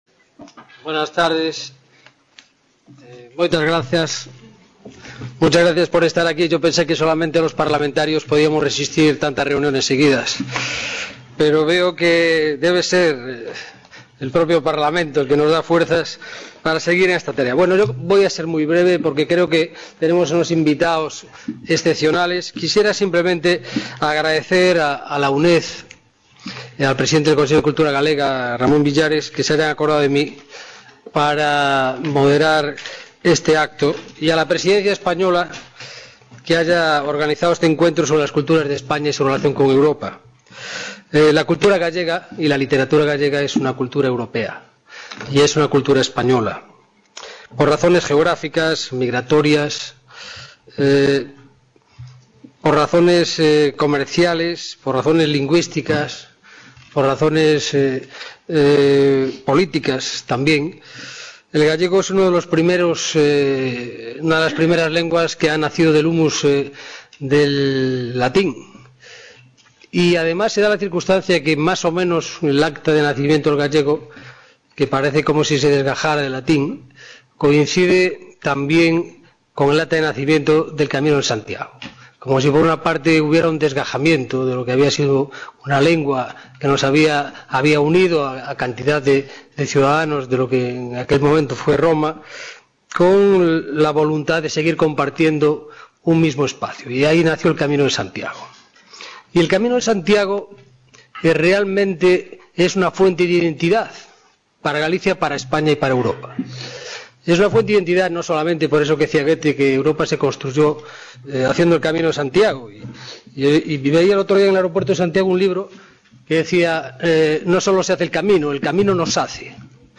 Inicio Mesa redonda: Literatura gallega y Europa
MODERADOR: Antolín Sánchez Presedo, Diputado del Parlamento Europeo
| Red: UNED | Centro: UNED | Asig: Reunion, debate, coloquio...